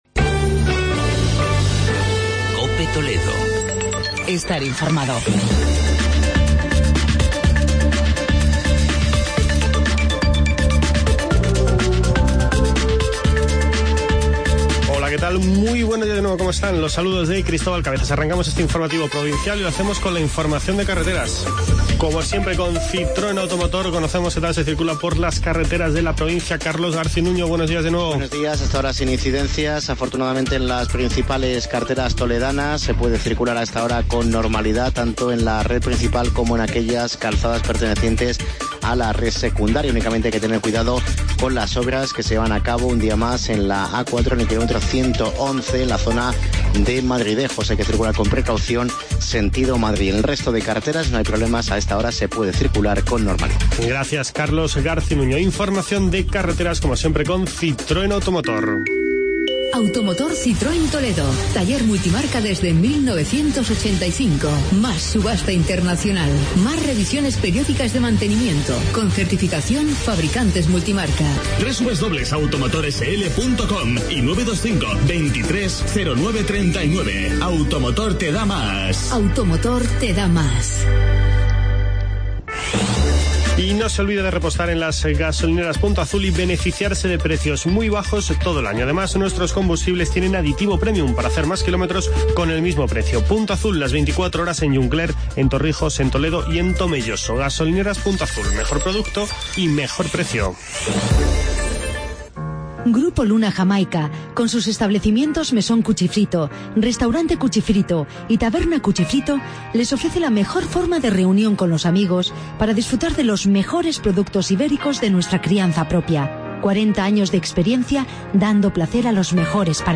Informativo provincial